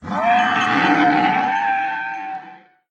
sounds / mob / horse / zombie / death.mp3
death.mp3